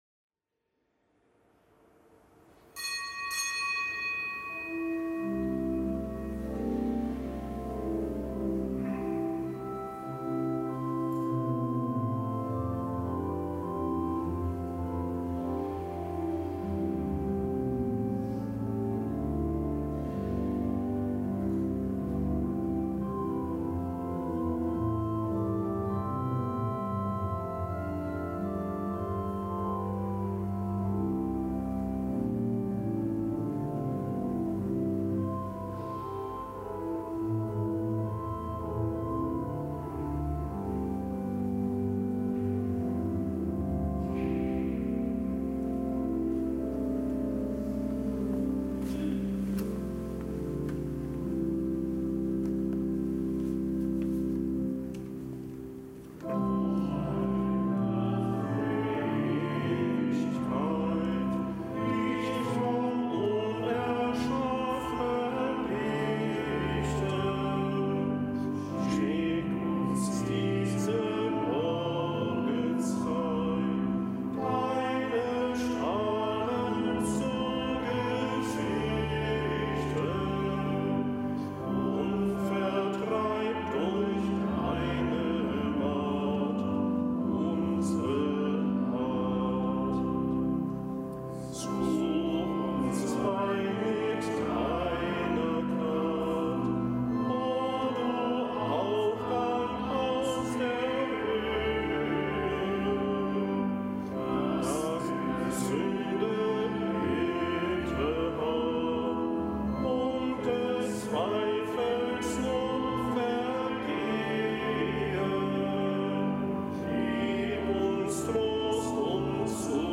Kapitelsmesse am Donnerstag der zweiten Woche im Jahreskreis
Kapitelsmesse aus dem Kölner Dom am Donnerstag der zweiten Woche im Jahreskreis, Nichtgebotener Gedenktag Heiliger Vinzenz, Diakon, Märtyrer in Spanien